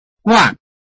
拼音查詢：【饒平腔】guad ~請點選不同聲調拼音聽聽看!(例字漢字部分屬參考性質)